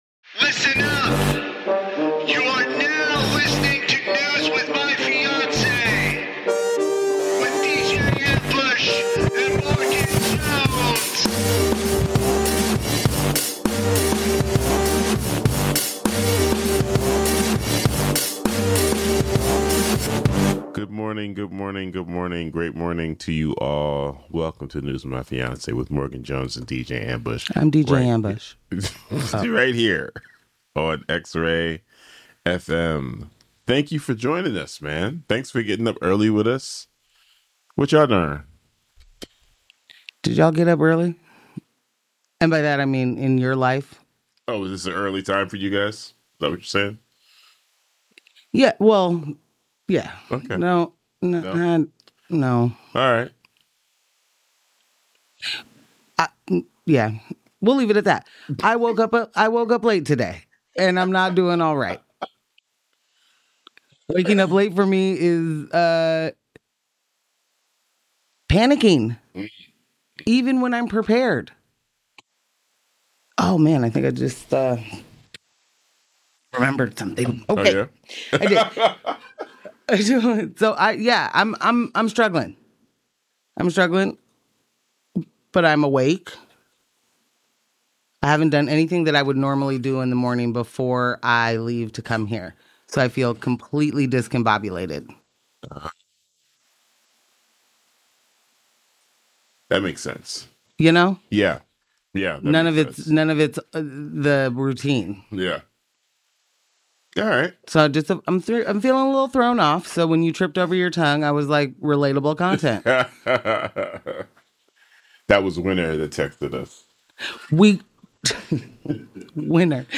*reggae horns*